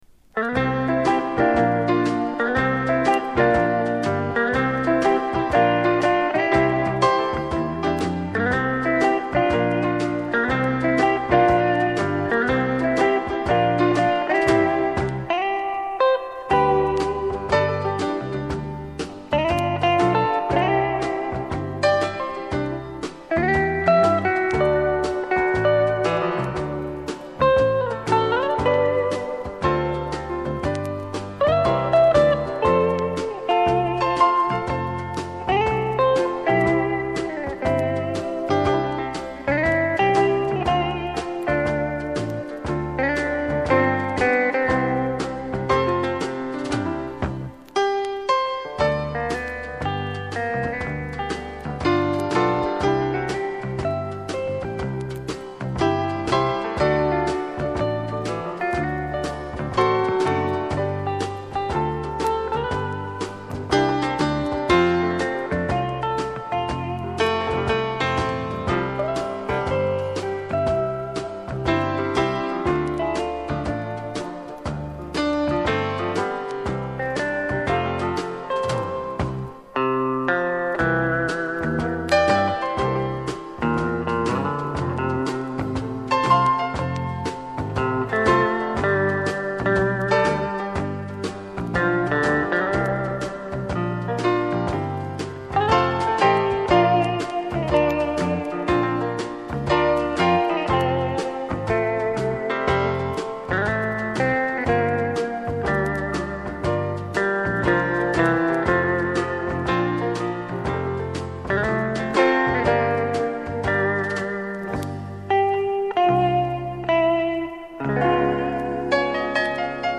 Известный гитарист.